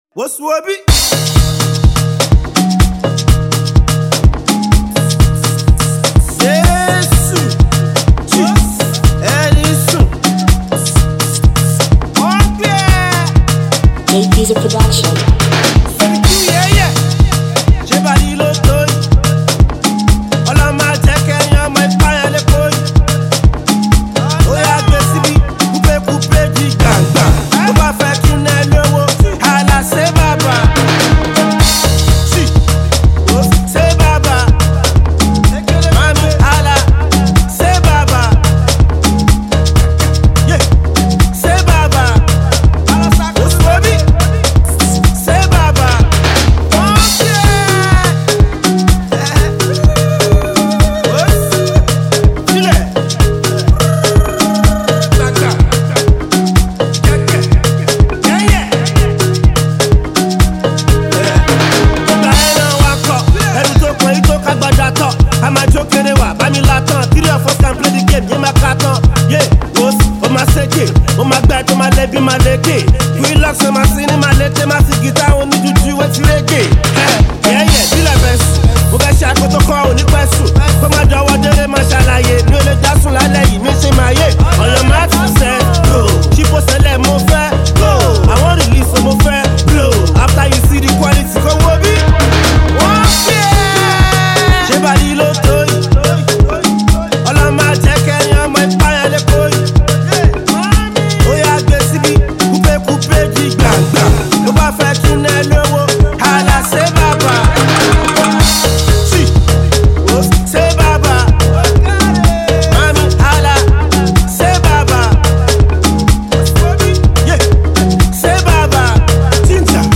Alternative Rap
has an uptempo club like production
witty, funny and sometimes lewd rhymes